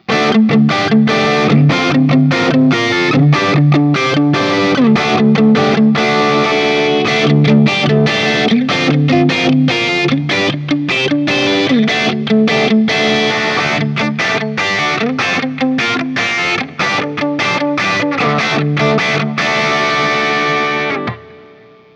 JCM-800
A Barre Chords
I’ve had this guitar for some time and I had made the recordings using my Axe-FX II XL+ setup through the QSC K12 speaker recorded direct into my Macbook Pro using Audacity.
For the first few recordings I cycled through the neck pickup, both pickups, and finally the bridge pickup.